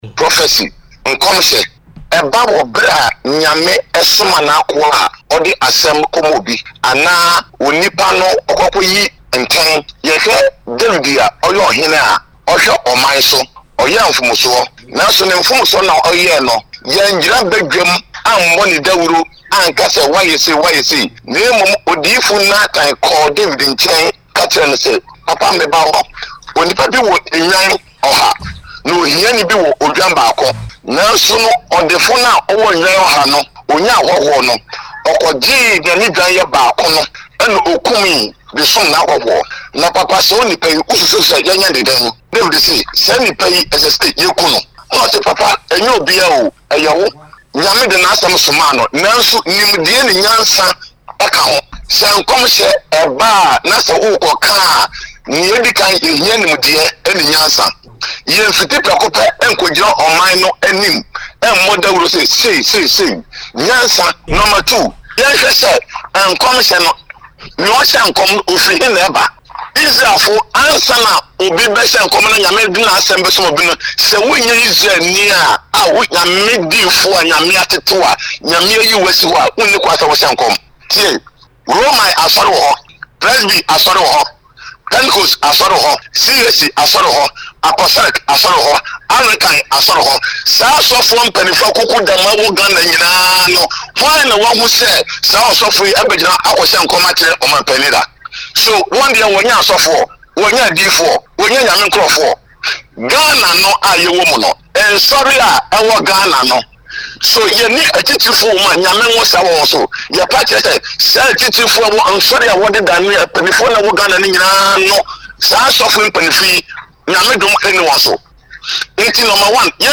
phone interview